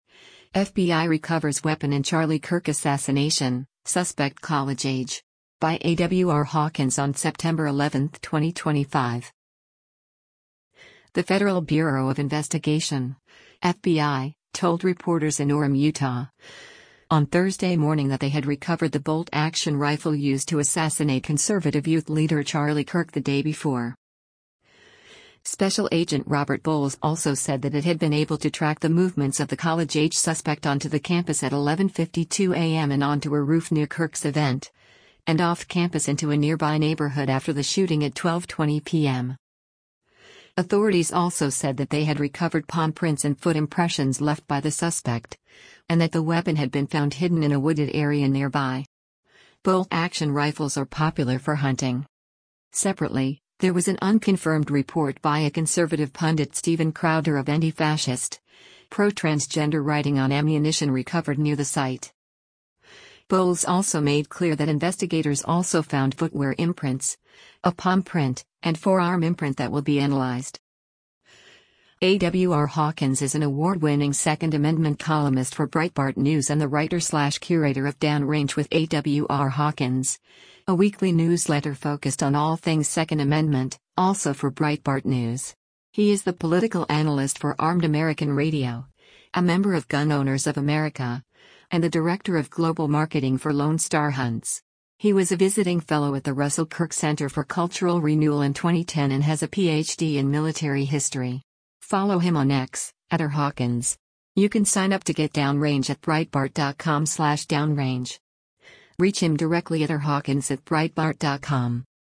The Federal Bureau of Investigation (FBI) told reporters in Orem, Utah, on Thursday morning that they had recovered the bolt-action rifle used to assassinate conservative youth leader Charlie Kirk the day before.